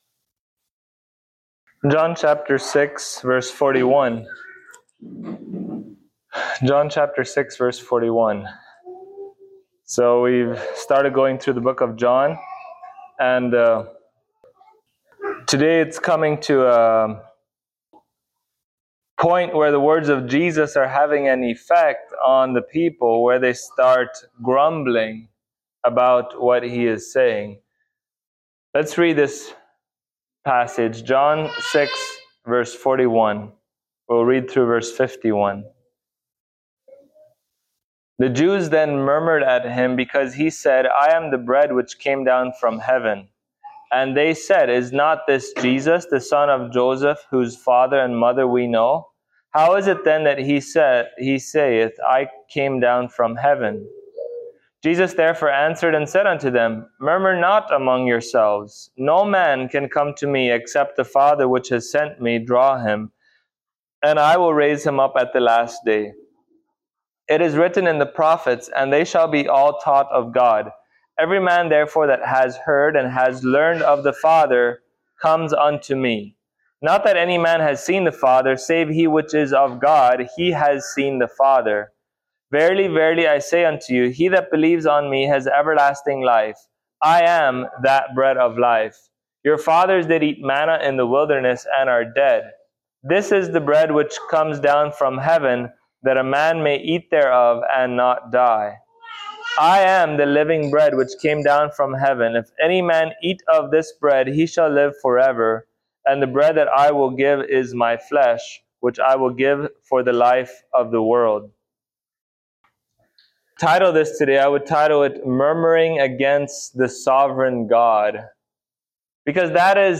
John Passage: John 6:41-51 Service Type: Sunday Morning Topics